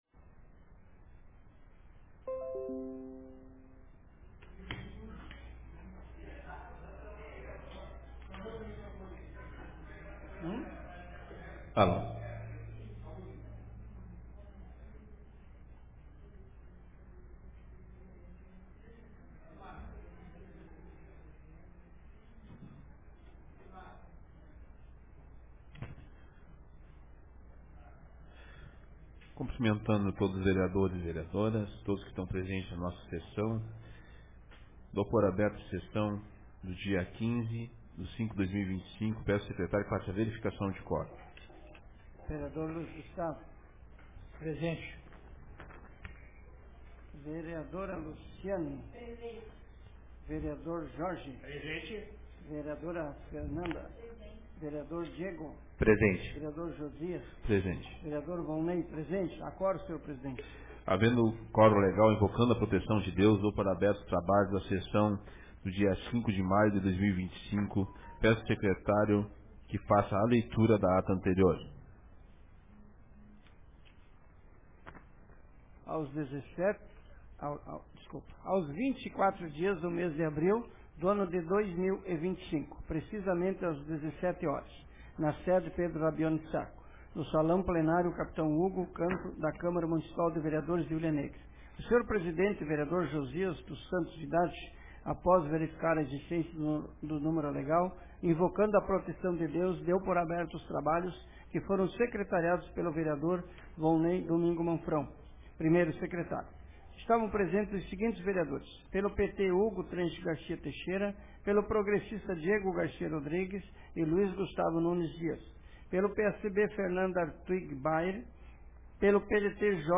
Sessão Ordinária da Câmara de Vereadores de Hulha Negra Data: 15 de maio de 2025